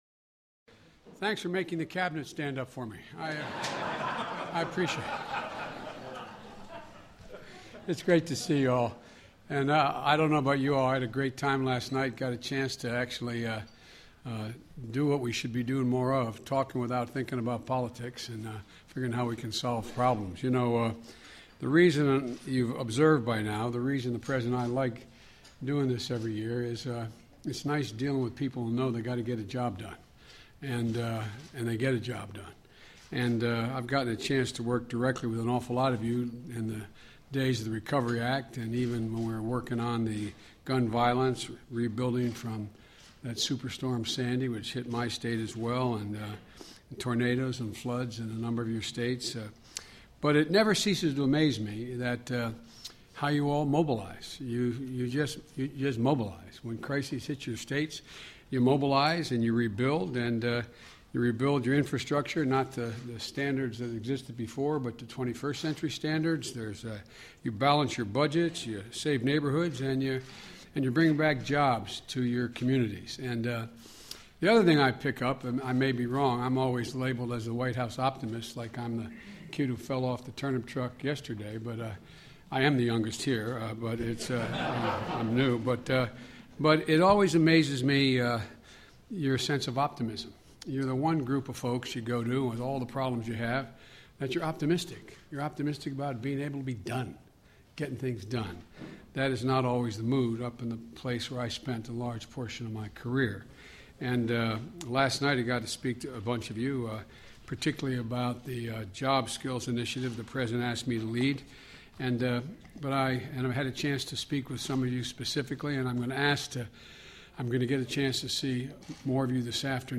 U.S. President Barack Obama and Vice President Joe Biden addresses the National Governors Association